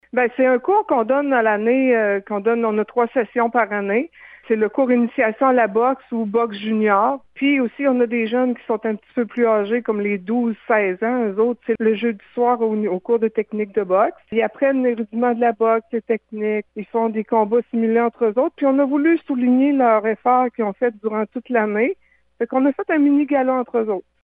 L’une des enseignantes